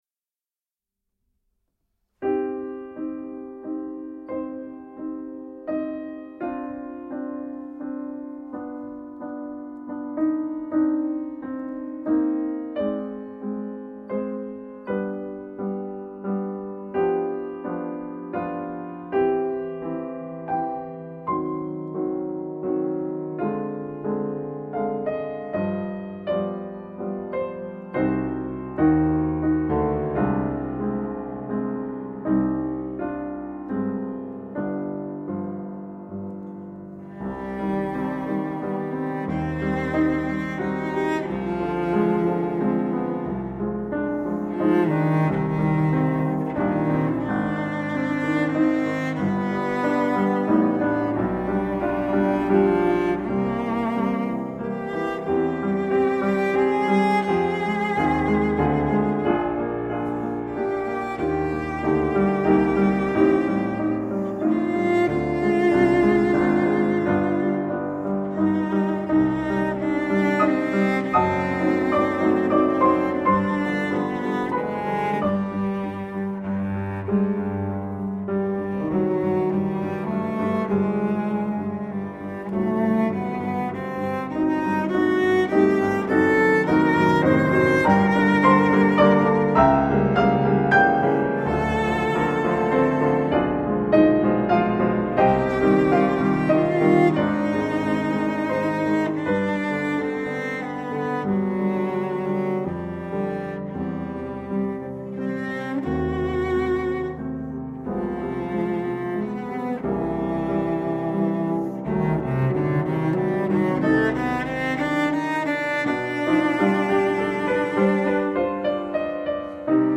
wiolonczela
fortepian